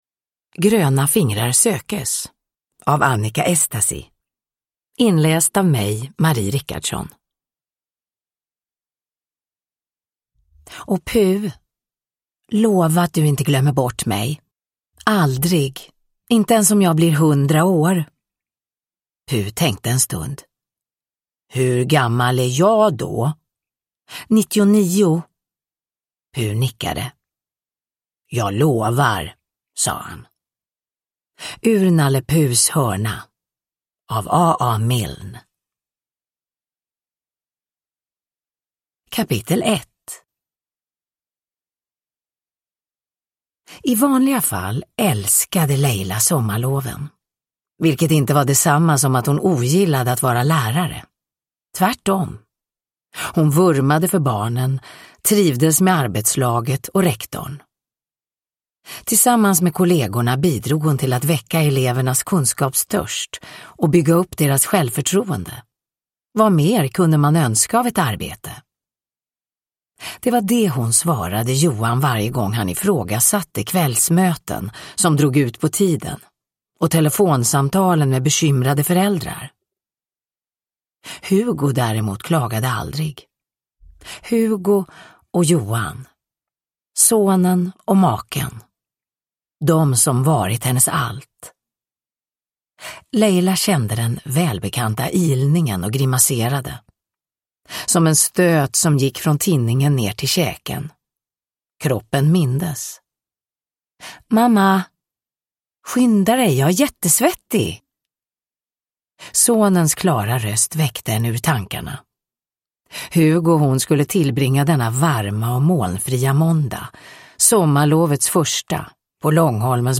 Gröna fingrar sökes – Ljudbok – Laddas ner
Uppläsare: Marie Richardson